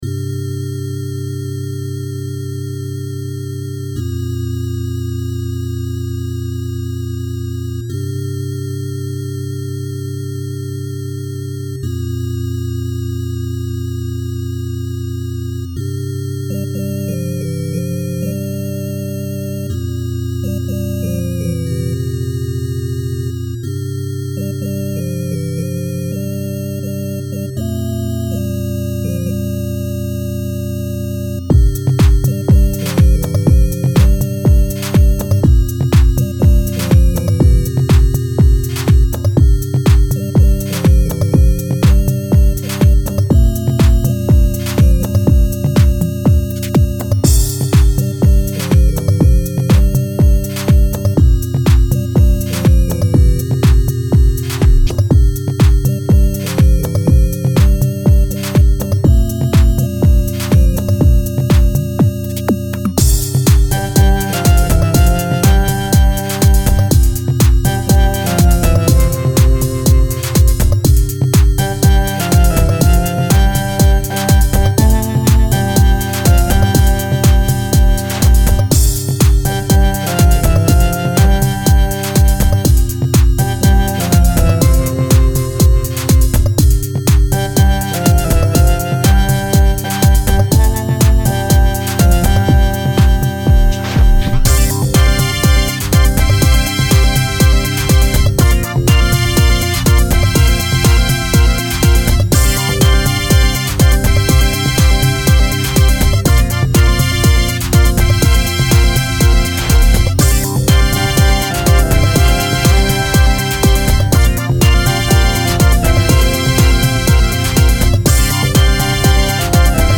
Categories: Electronica, Dance, Downtempo and Ambient